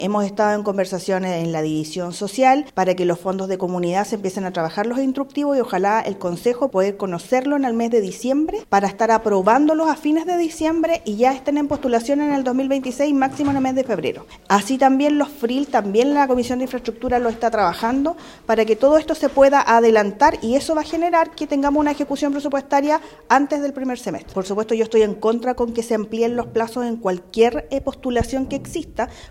Desde la Comisión de Hacienda, su presidenta, la consejera republicana Yasna Vásquez, hizo un llamado a los municipios a respetar los plazos de postulación para evitar futuros recortes.
presupuesto-los-lagos-republicana.mp3